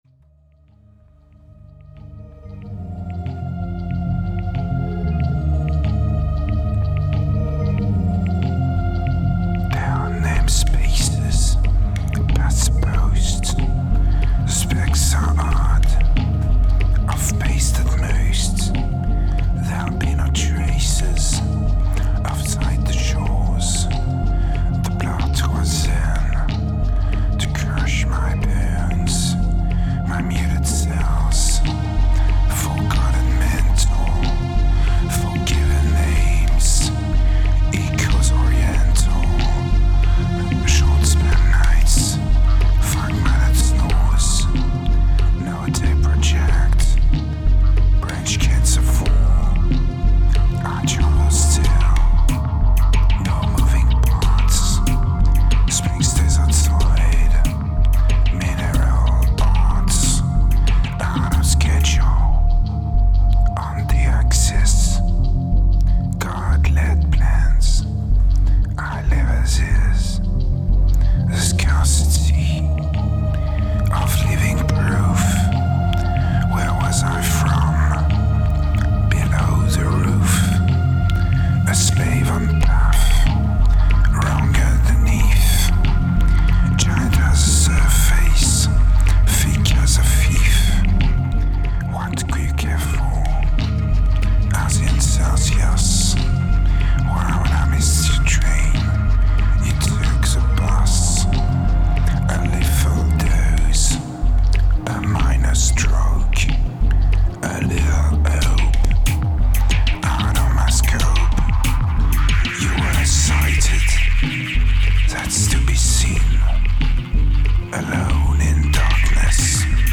German Lyrics Peaceless Whispers English Ambient Song Vocals